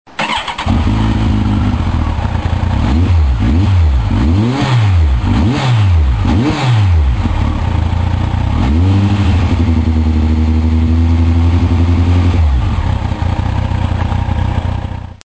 音は車検対応マフラーなのでそんなにうるさくはないです。
ノーマルよりは勿論大きいですが程々といったところ。
とはいえ、低音寄りの音質になりけっこう響きますので特に早朝夜間には配慮は必要だと思います。
全体的には集合管のようなヴォンヴォン言う感じの良い音です。
排気音(空ぶかしで4000rpm位まで・MP3形式）